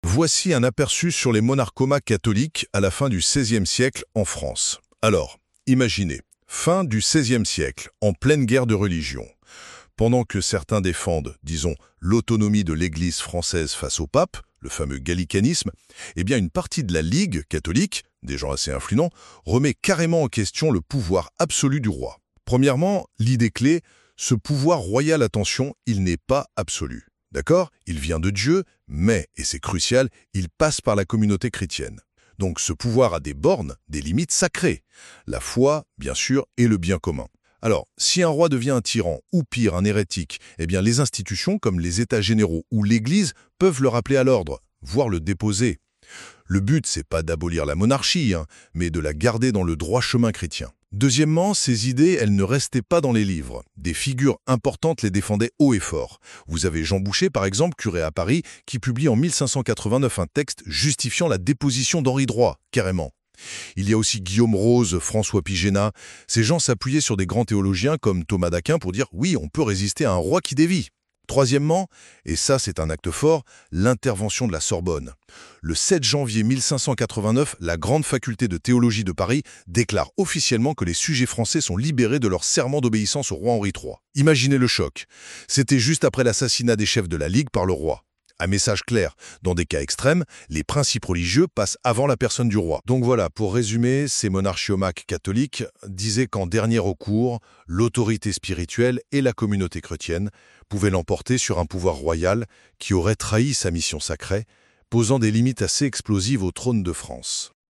🎙 Antenna I.O. Vox Frequencia (Capsule auditive) :
On entendra, en sourdine, le Regnans in Excelsis de saint Pie V, ton de bronze !